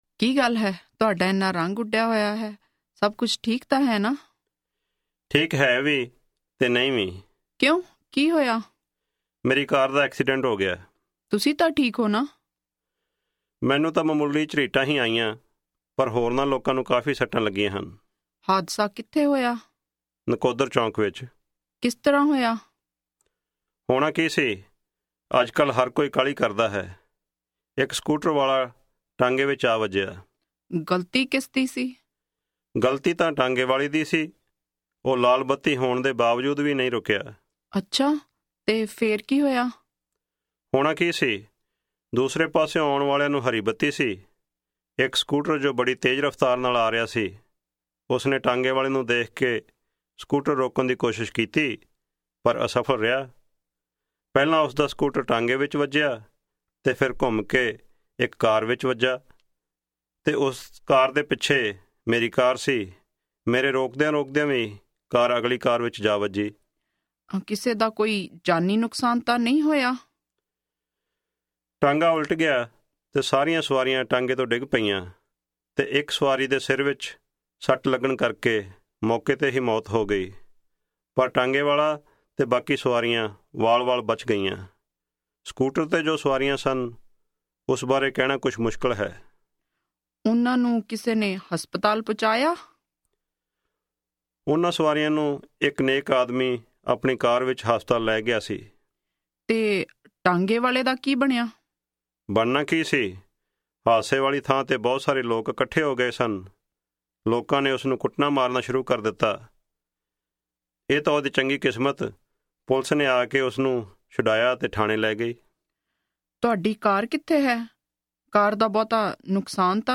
Punjabi Conversation 15 Listen
A husband explains all the details to his wife.